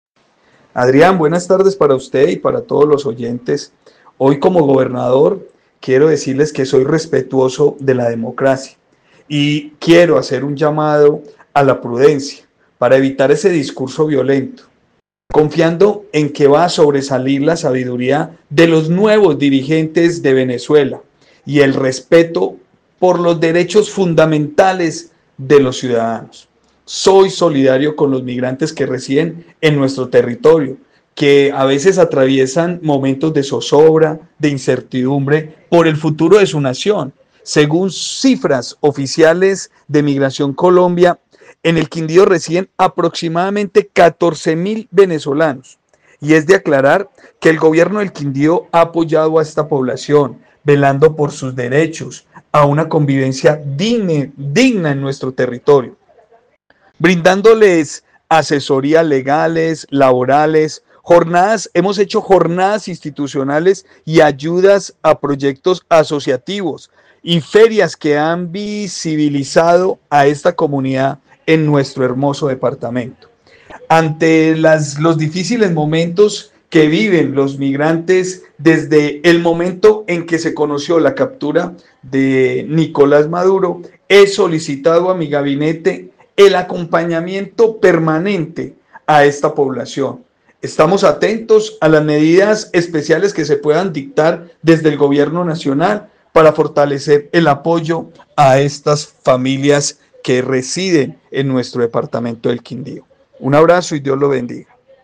Juan Miguel Galvis, gobernador del Quindío